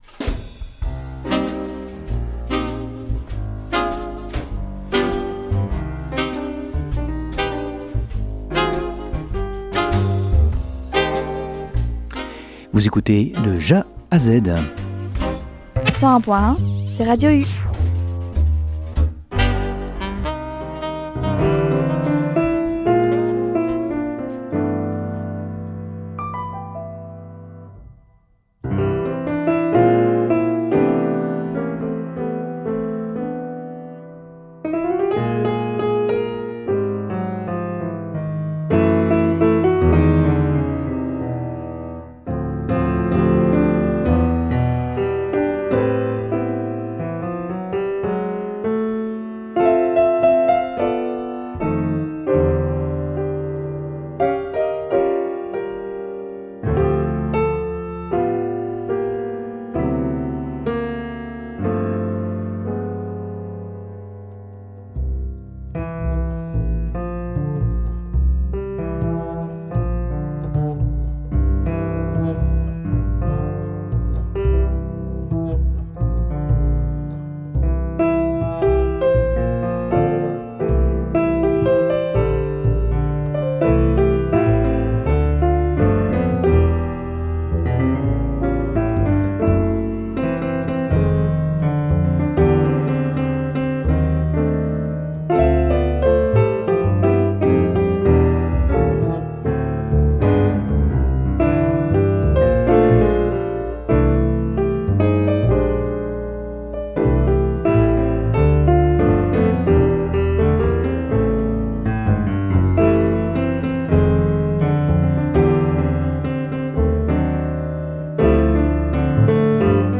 Retrouvez ma sélection JAZZ